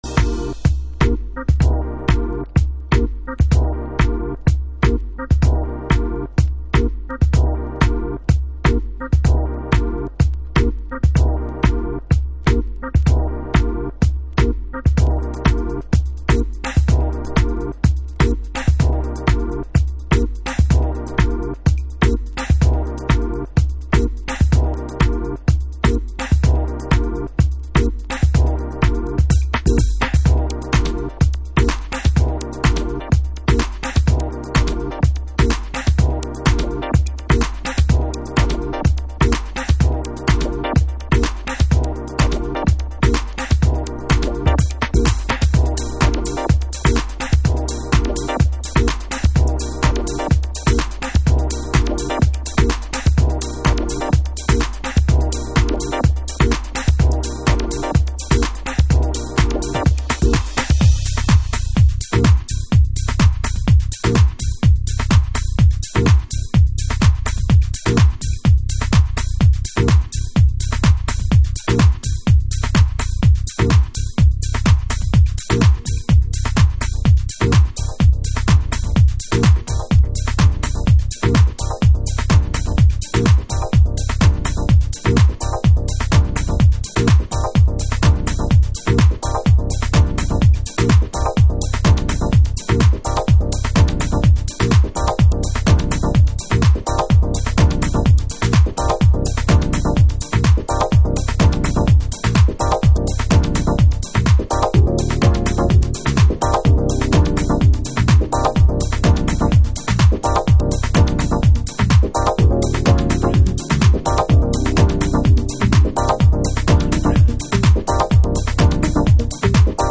House / Techno
スウィングするドラミングとシンプルなメッセージのリフレインが強く印象的なフロアを作り出します。